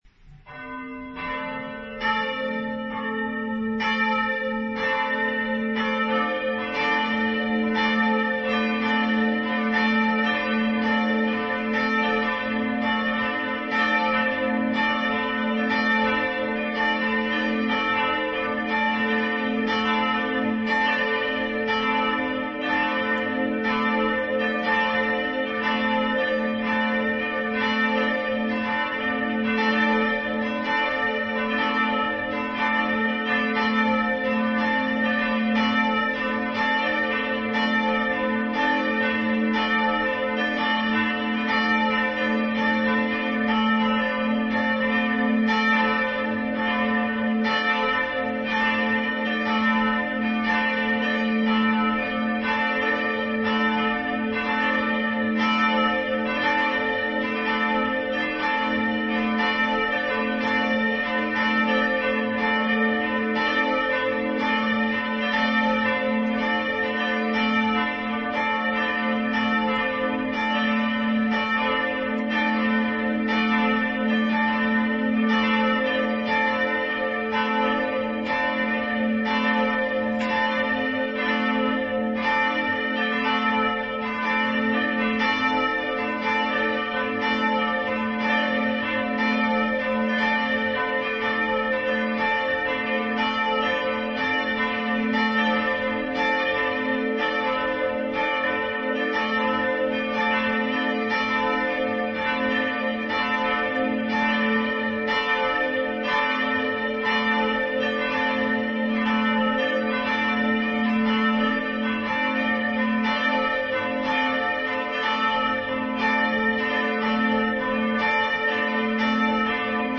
Die Glocken der Kirche – Ein Klang, der Geschichten erzählt
Lausche dem faszinierenden Zusammenspiel der Glocken beim „Zamm’schlagen“ und lass dich von ihrem zeitlosen Echo berühren.
Kirche-erLauschen-Glockenlaeuten_01.mp3